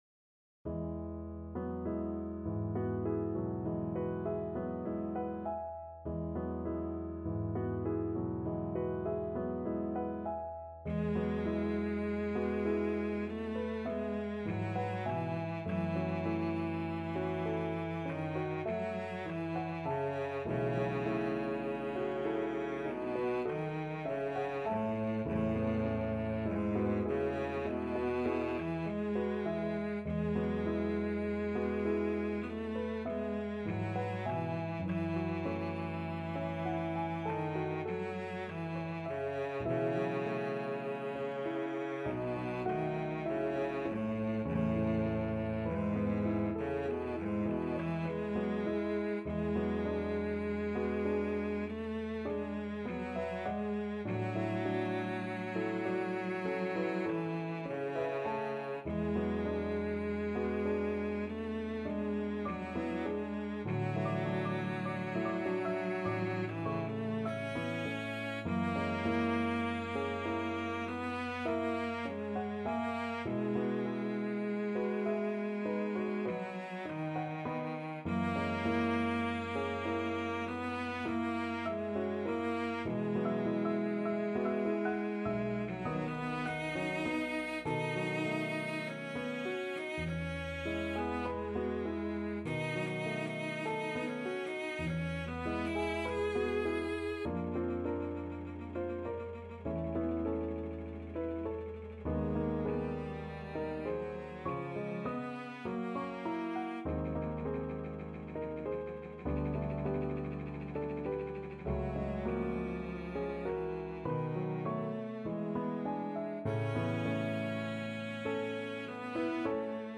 Classical Rimsky-Korsakov, Nikolai Nocturne from Pan Voyevoda Cello version
Cello
4/4 (View more 4/4 Music)
E major (Sounding Pitch) (View more E major Music for Cello )
Lento =50
Classical (View more Classical Cello Music)